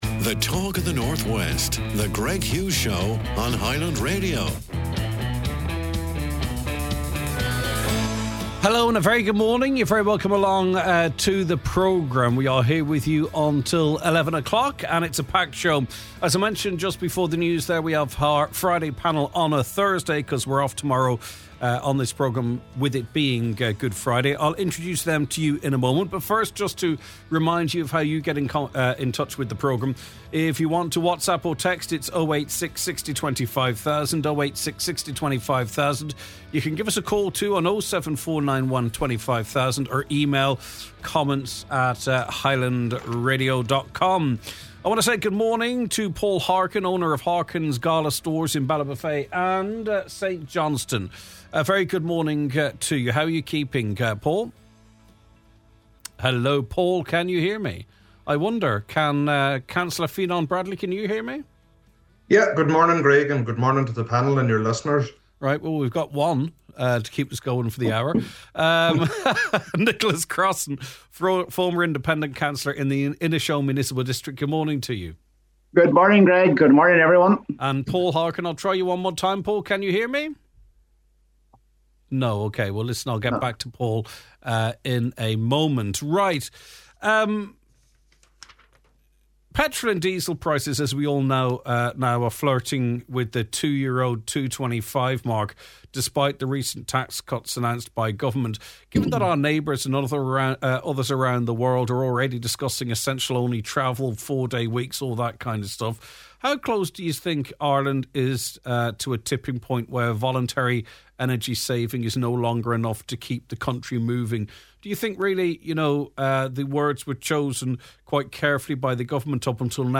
Here is what is coming up in this episode: 🎙 Hour 1: The Thursday Panel With the Easter weekend upon us, our panel gathers to dissect the biggest stories making the headlines this week.